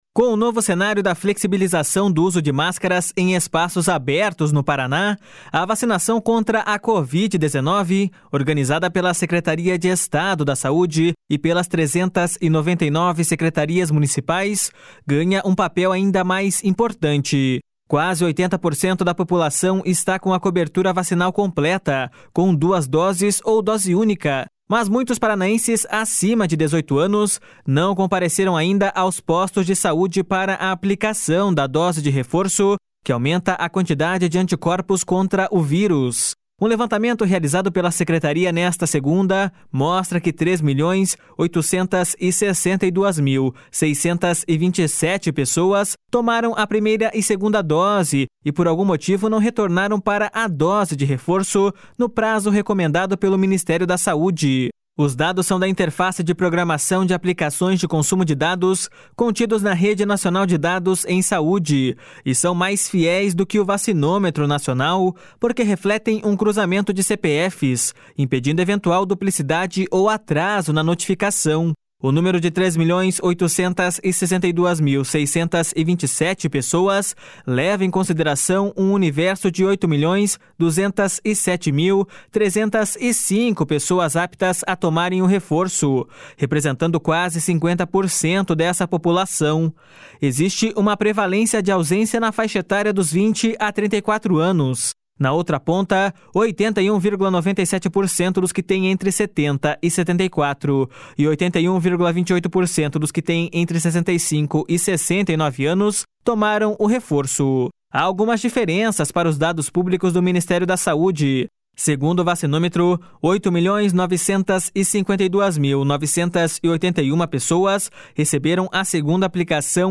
O secretário de Estado da Saúde, Beto Preto, destacou a importância de manter a vacinação em alta para a pandemia ficar em baixa.// SONORA BETO PRETO.//